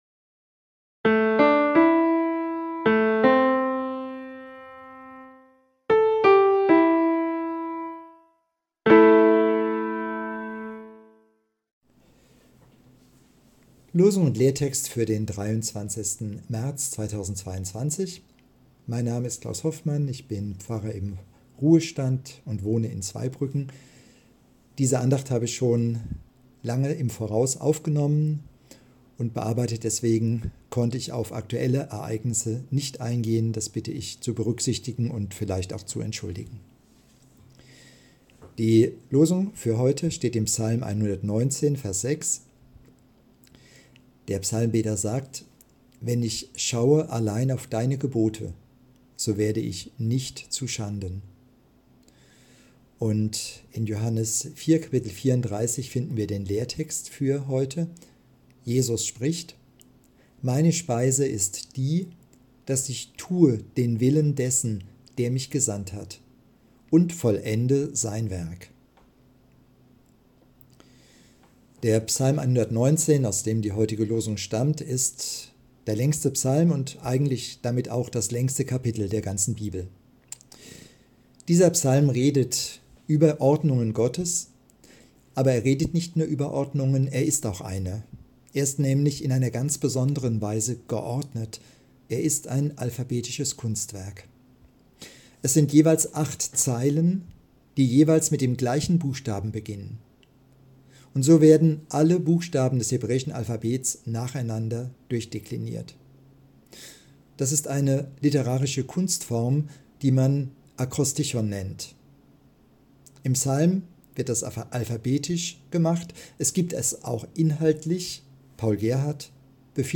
Losungsandacht für Mittwoch, 23.03.2022